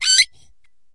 玻璃 陶瓷 " 陶瓷冰淇淋碗金属勺子在碗内发出刺耳的声音 02
描述：用金属勺刮擦陶瓷冰淇淋碗的内部。 用Tascam DR40录制。
标签： 刮下 金属勺 尖叫 刮去 勺子 尖叫 CE拉米奇 金属
声道立体声